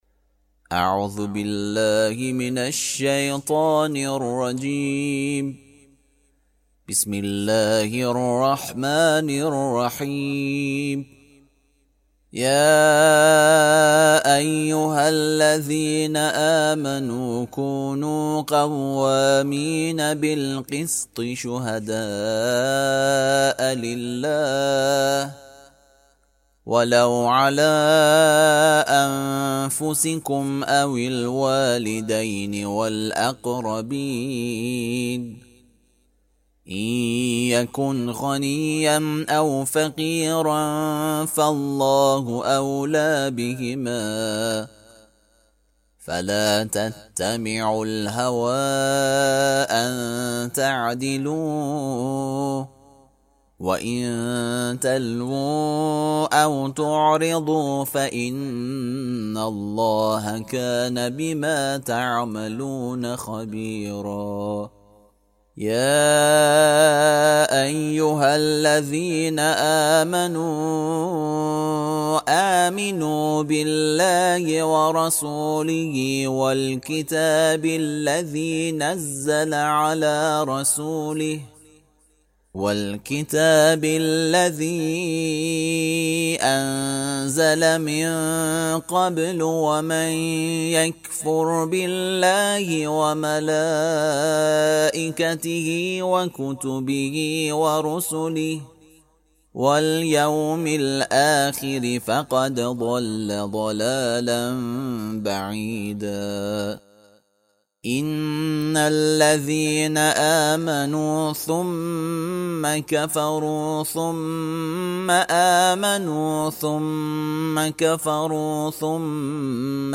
ترتیل صفحه ۱۰۰ سوره مبارکه نساء(جزء پنجم)